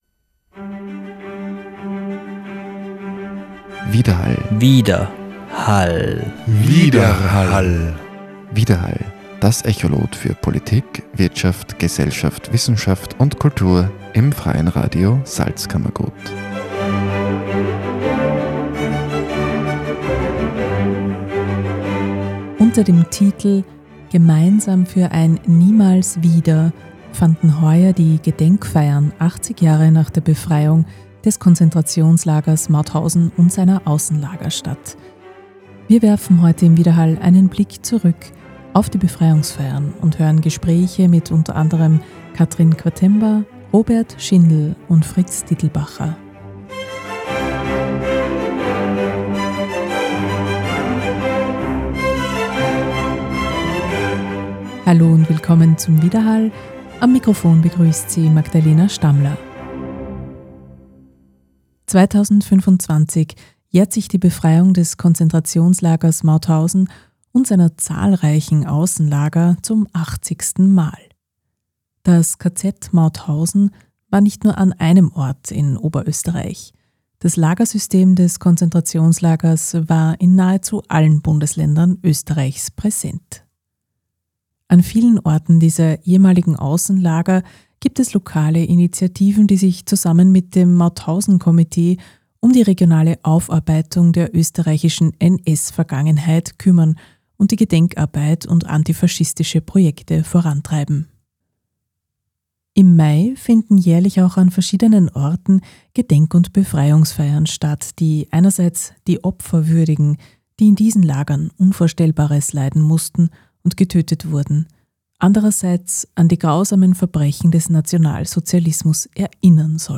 Gedenkfeiern mit dem Mikrofon begleitet
Interview